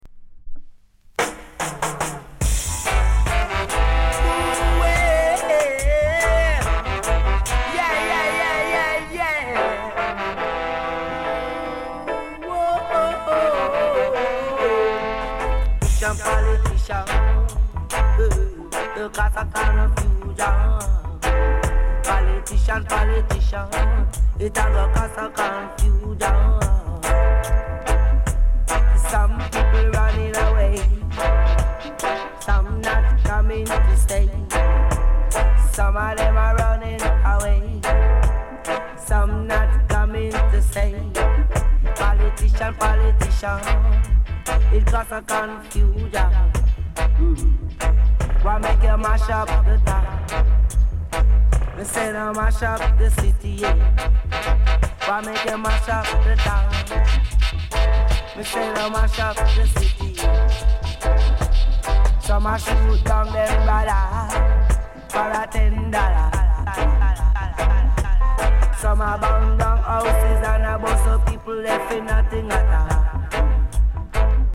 ジャマイカ盤 7inch/45s。